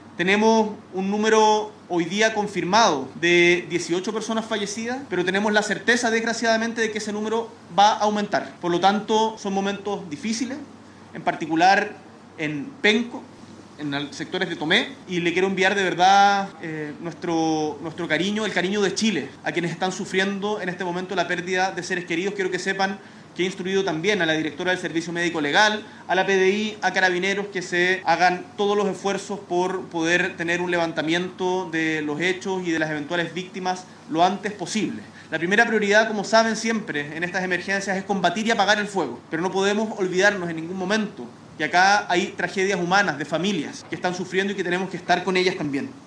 Desde la región del Biobío, el Presidente confirmó la cifra de víctimas fatales y expresó sus condolencias a los familiares. “Tenemos hoy confirmadas 18 personas fallecidas, pero lamentablemente tenemos la certeza de que ese número va a aumentar”, señaló, agregando que la situación es especialmente compleja en Penco y sectores de Tomé.